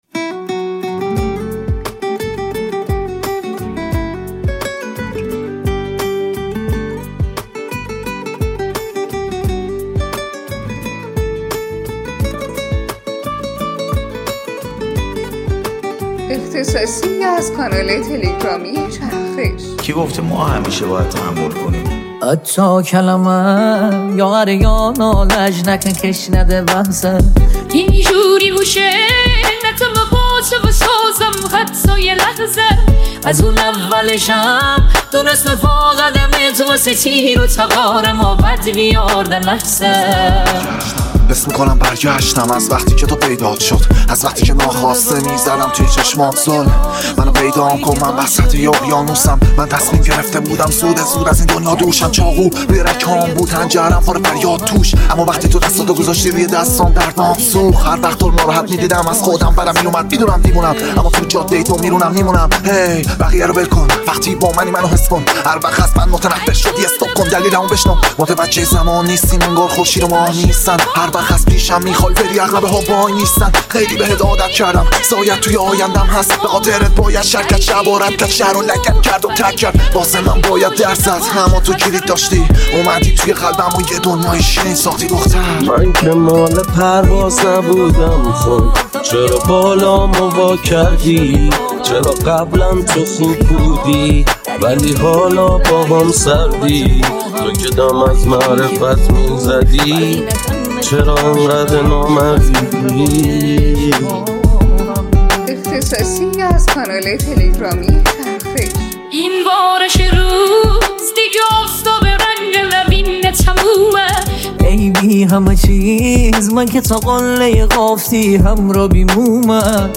ژانر: رپ.پاپ